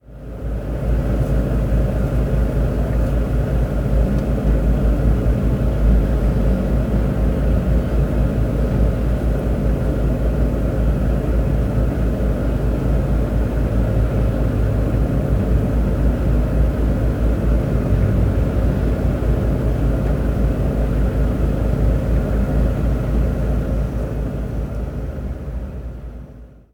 Coche Golf al ralentí desde el interior del coche
motor
ralentí
Sonidos: Transportes